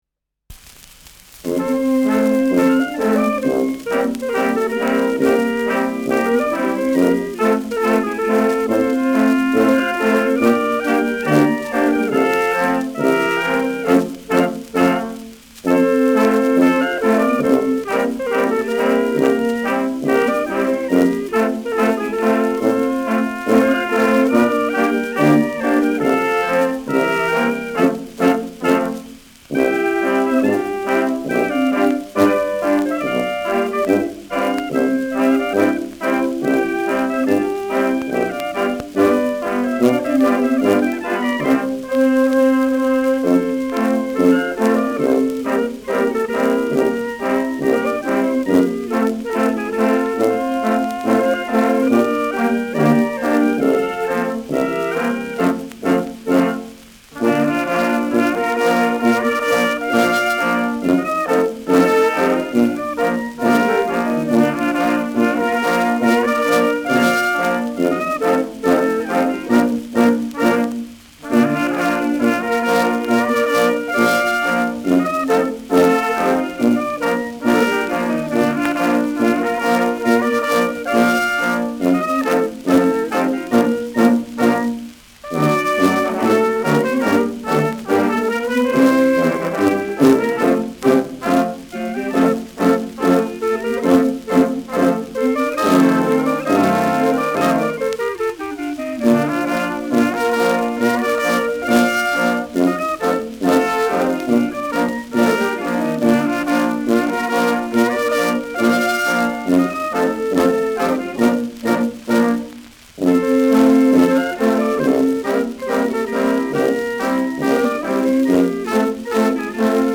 Schellackplatte
leichtes Rauschen : leichtes Knistern : leichtes Leiern